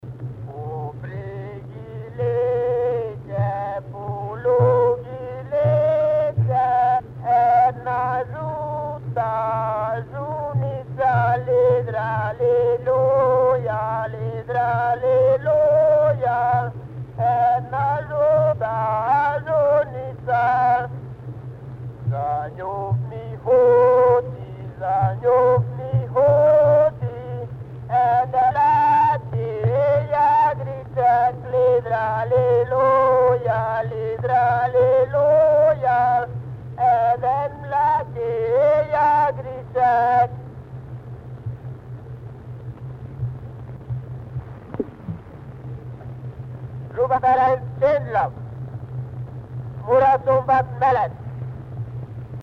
Место: Тишина/Csendlak
Сакупљач: Бела Викар (Béla Vikár)